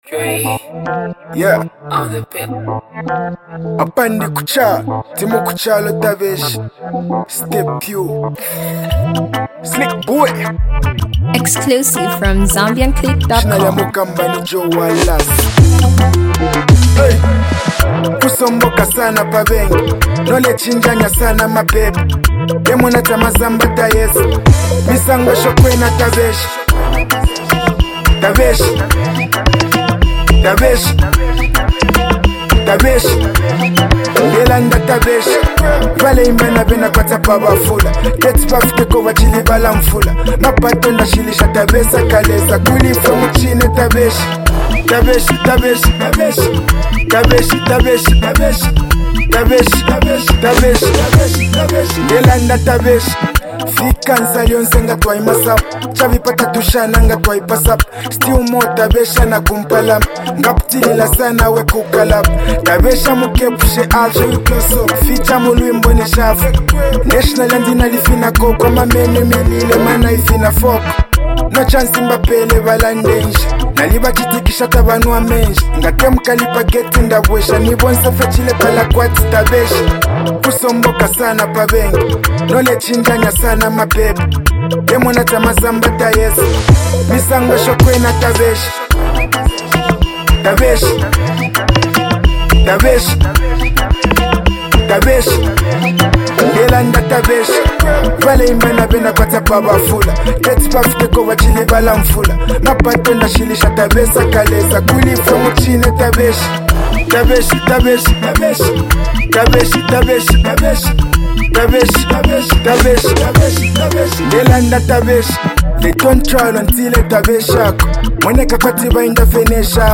country groove and street anthem